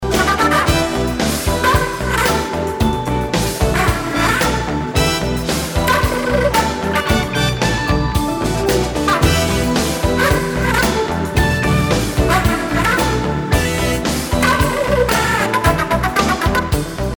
Собственно звук "рваной" дудки интересует